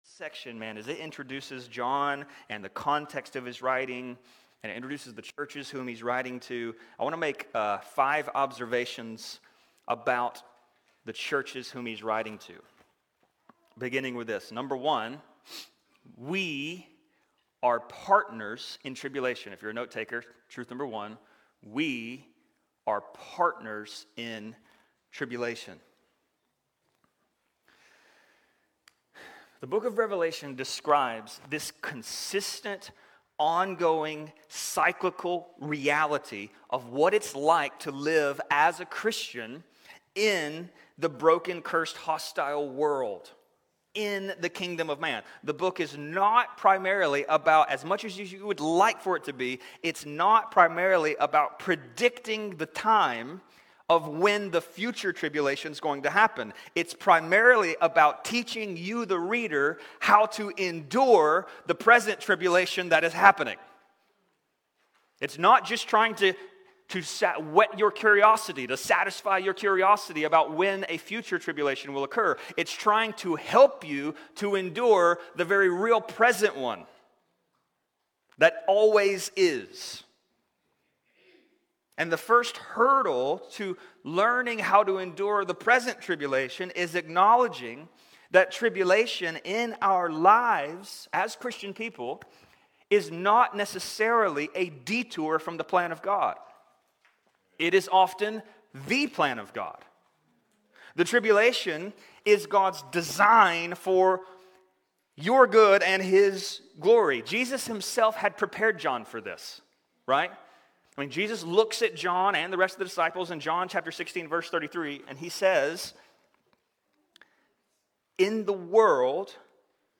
Sermons | St. Rose Community Church